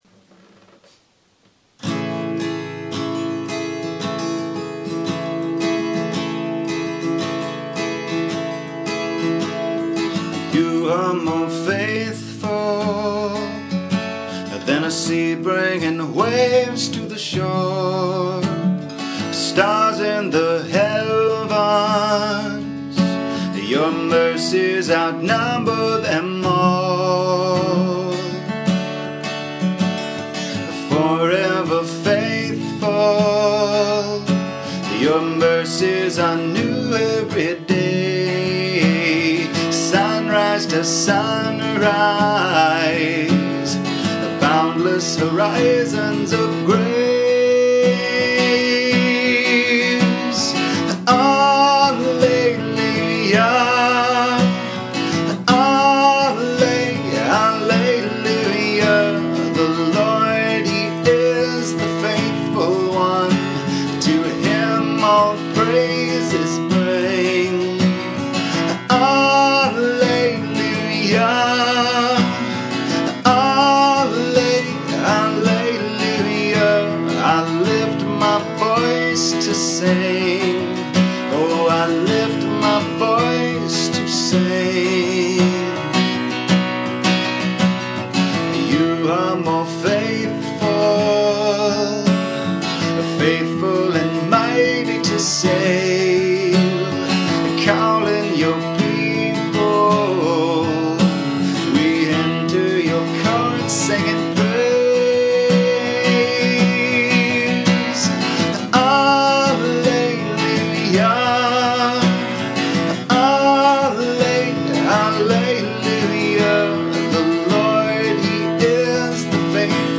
original worship songs